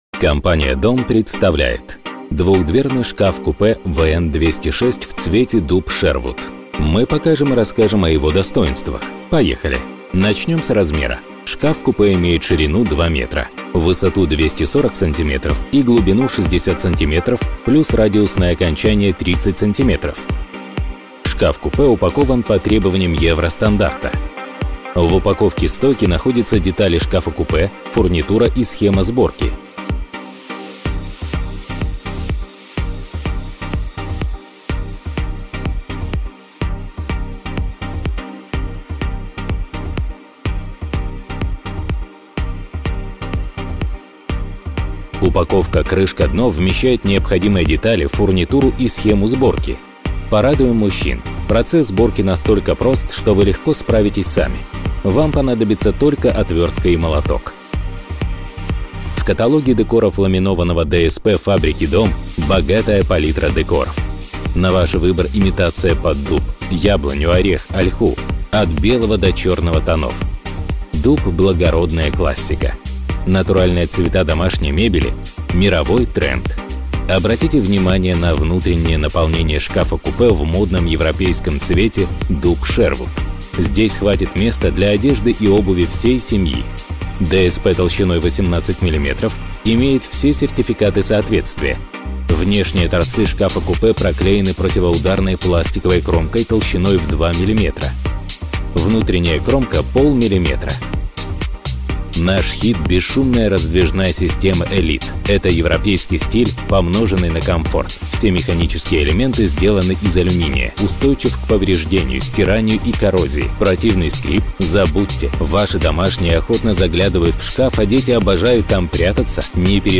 电台主播